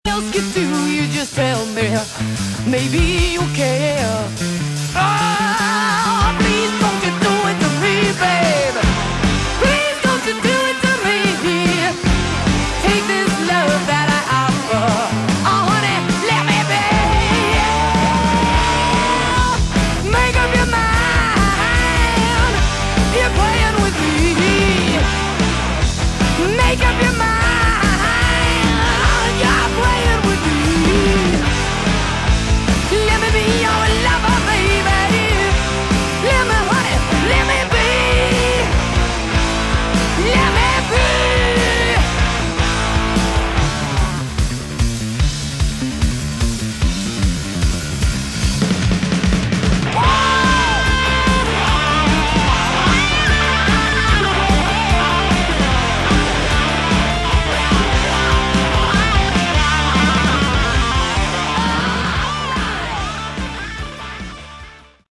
Category: Hard Rock
vocals
guitar
drums
keyboards, bass